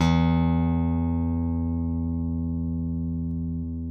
ALEM PICK E2.wav